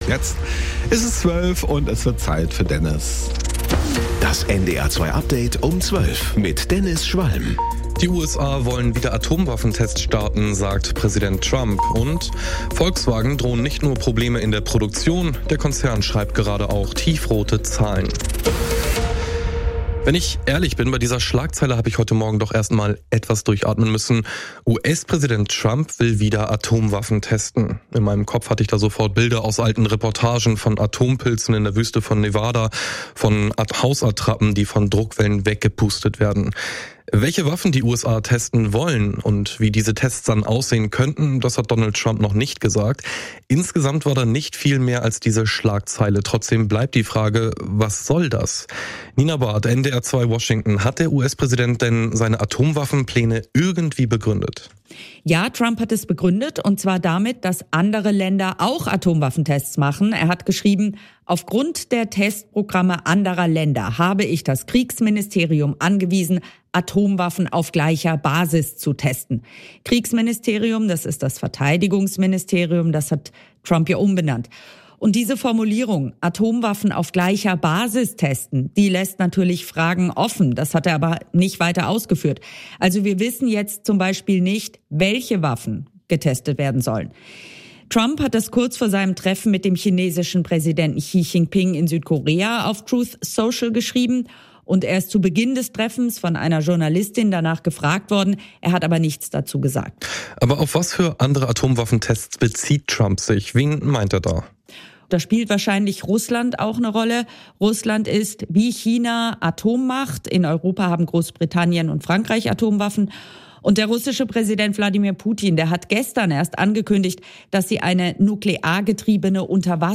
NDR 2 Tägliche Nachrichten Nachrichten NDR News Kurier Um 12 Update Um 12